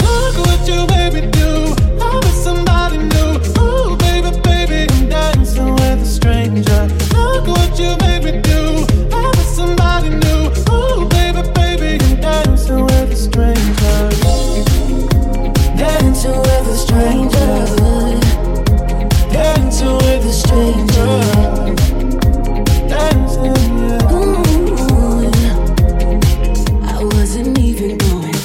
Genere: pop, latin pop, remix